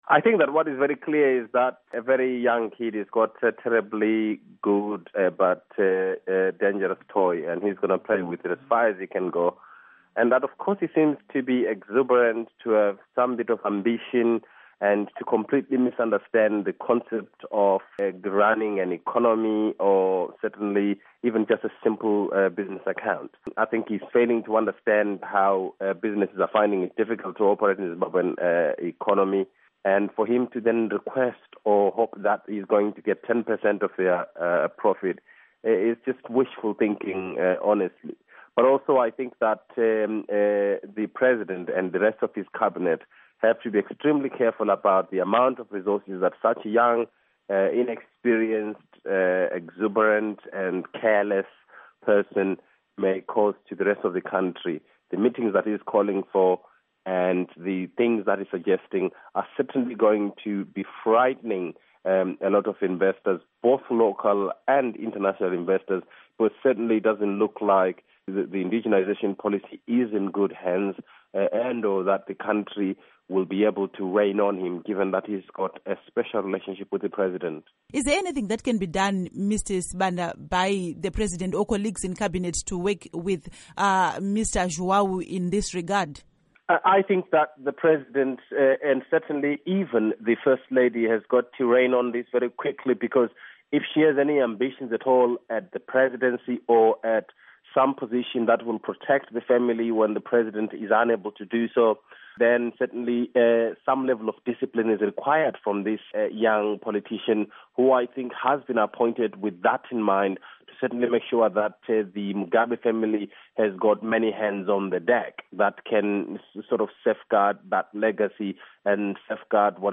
Political commentator
Interview